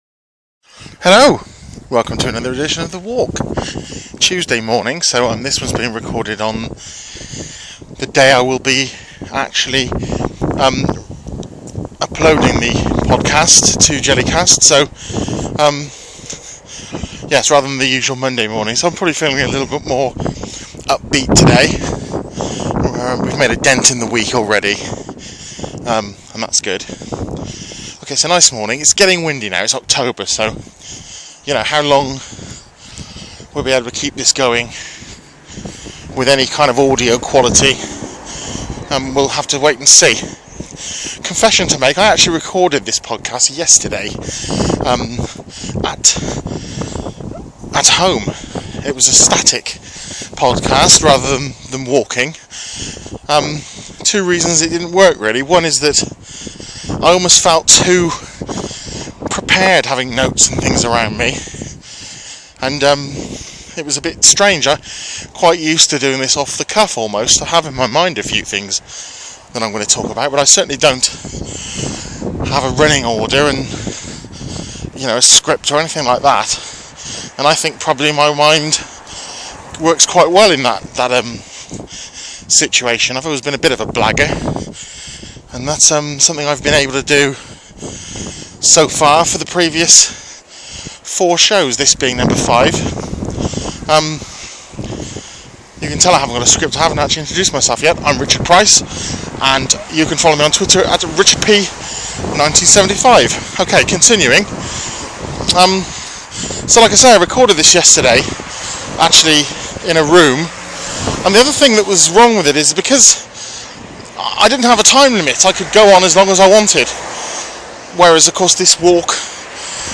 Listen to me as I walk to work and mull over stuff in an attempt to amuse and entertain.
This week: - your workplace dislikes - diet update - the 3 Golden Rules of lying - the liar This podcast is recorded live on location and unedited. It will feature traffic noise, a fat bloke out of puff and atmospheric conditions.